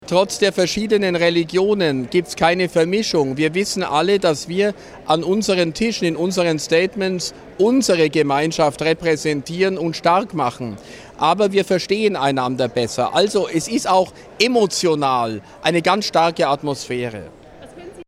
bischof_bertram_in_bologna.mp3